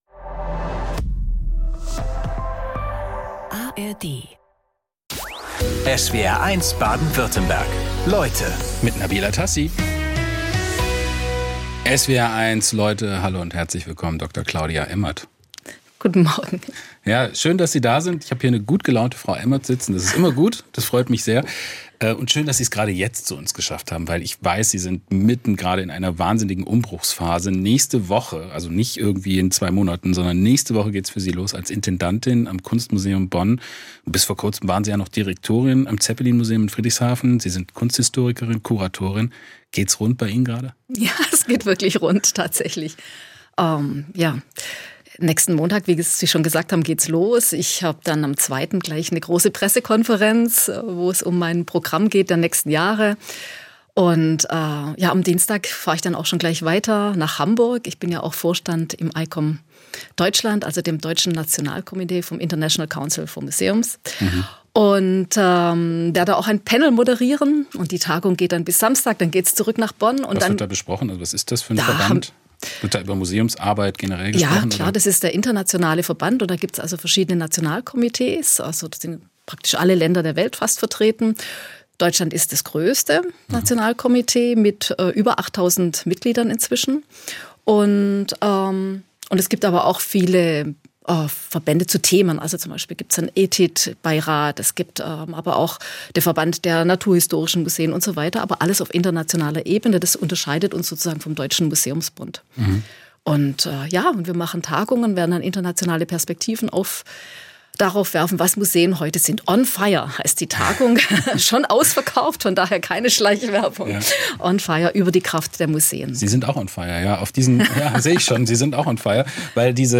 Talks mit besonderen Menschen und ihren fesselnden Lebensgeschichten aus Politik, Sport, Wirtschaft oder Wissenschaft.